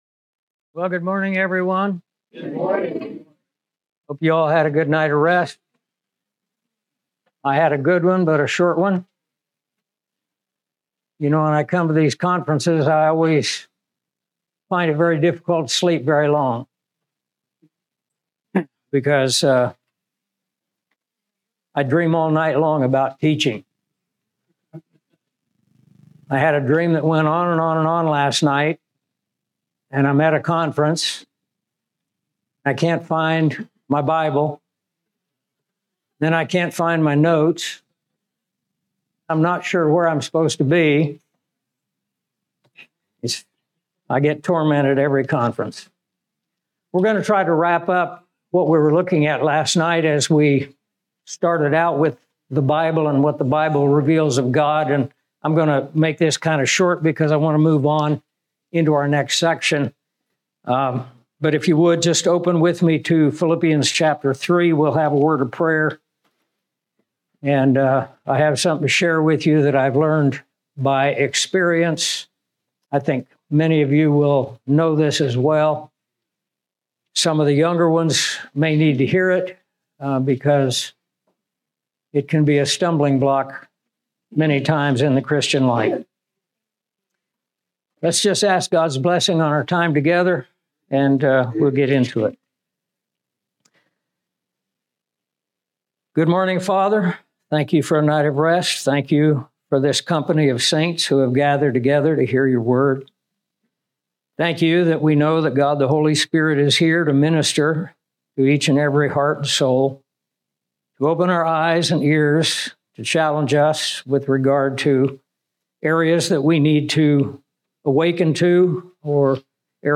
This Bible conference featured seven lesson extracted from The Basics Book.